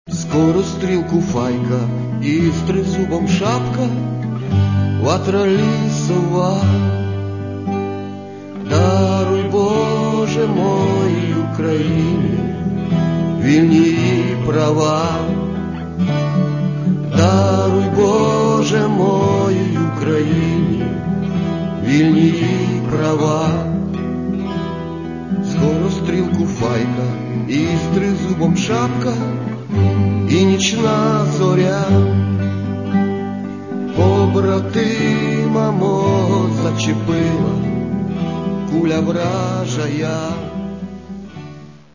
Каталог -> Рок та альтернатива -> Поетичний рок